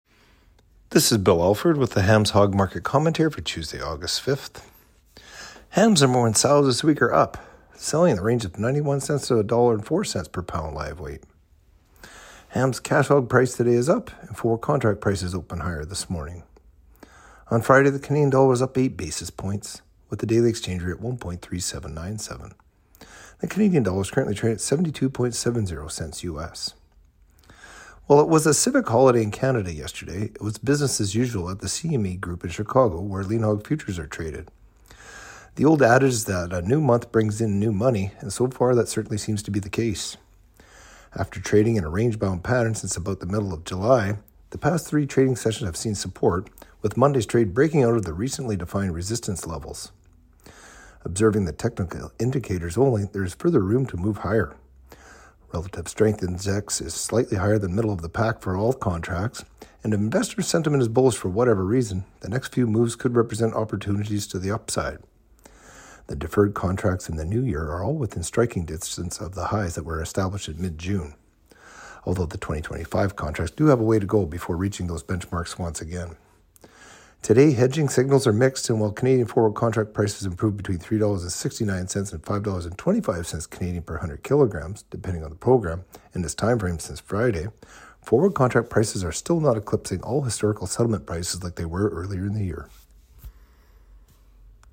Hog-Market-Commentary-Aug.-5-25.mp3